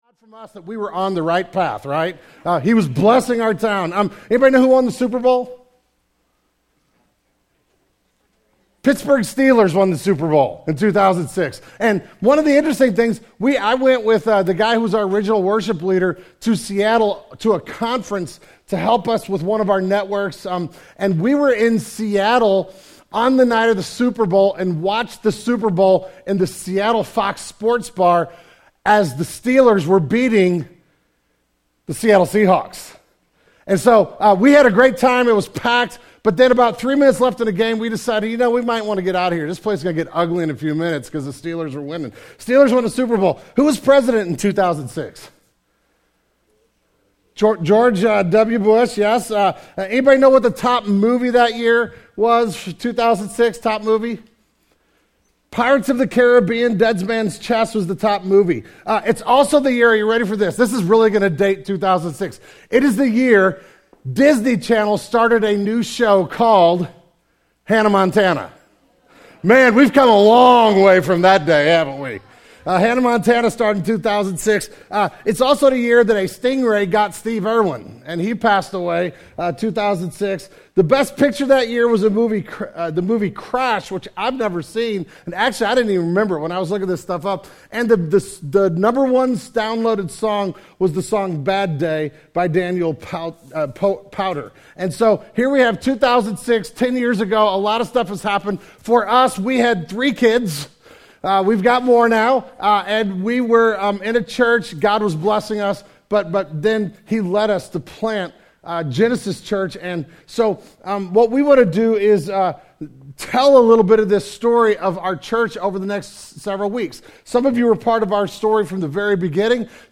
The February 2016 Sermon Audio archive of Genesis Church.